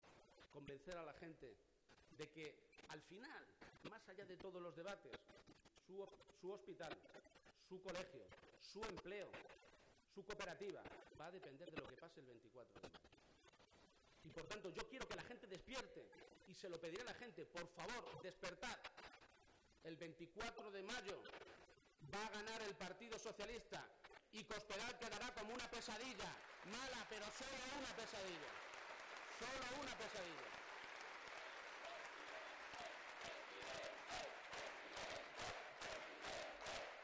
García-Page hacía esta mañana en el Teatro Auditorio de Cuenca la presentación de su candidatura.